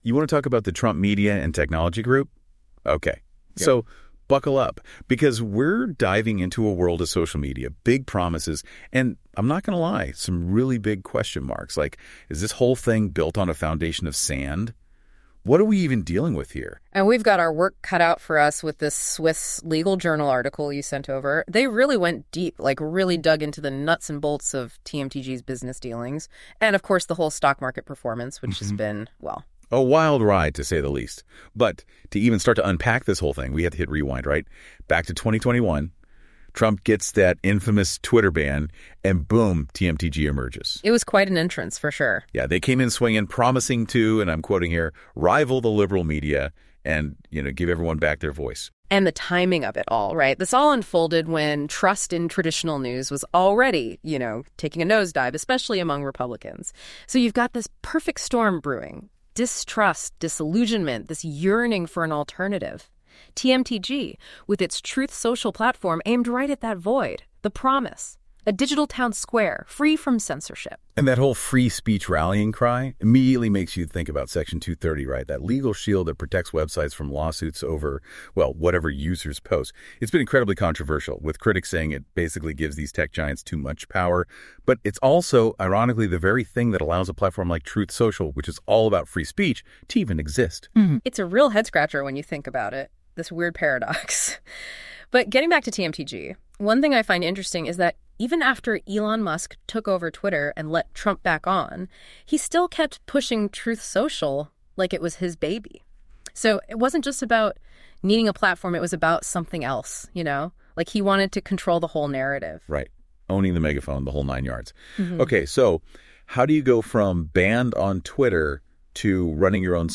Is it a bold investment or a risky gamble? Tune in for a quick, AI-generated breakdown of this hot topic in capital markets law.